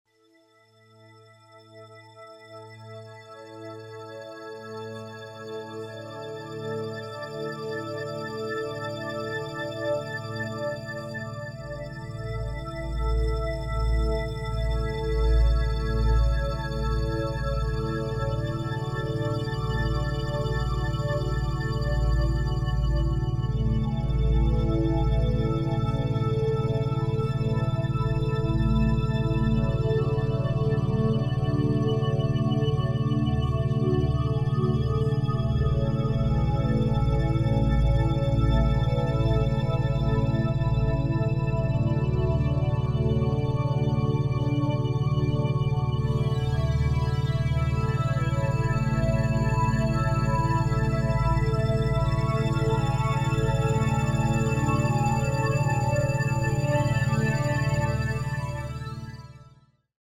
דוגמת מוזיקה בקלטת השנייה עם ותדר איזוכרוני להצלחה:
הצלחה לעצמאים עם מוזיקה ותדר הצלחה
תדר איזוכרוני למשיכת הצלחה: